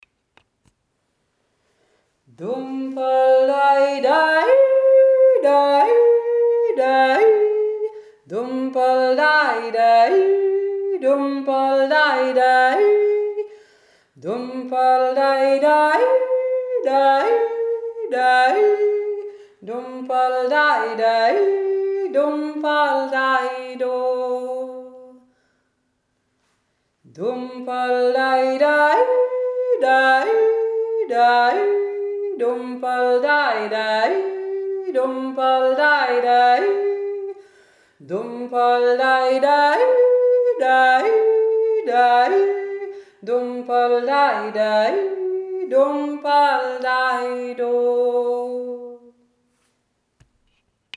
Frühlingserwachen 2017 Virgen Osttirol